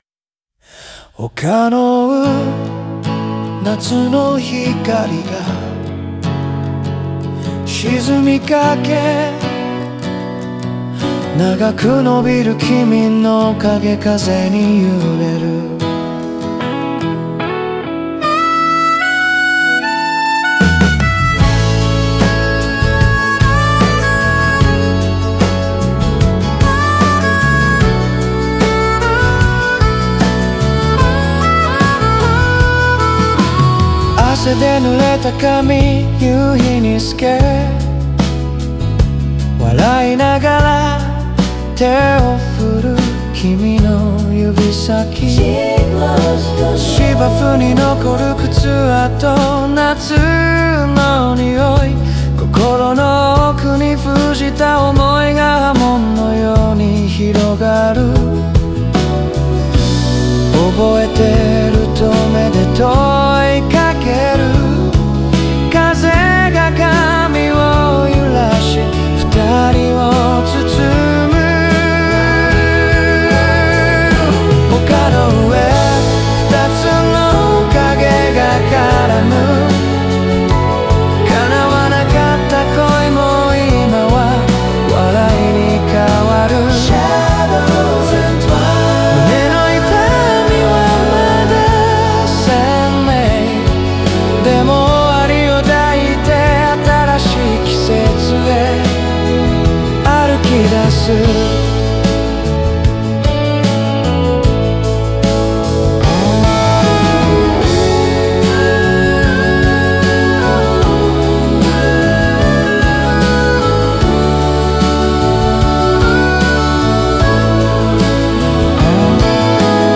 温かいハーモニーと柔らかい楽器の組み合わせで、聴き手に郷愁と安心感を与える構成になっている。